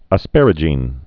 (ə-spărə-jēn)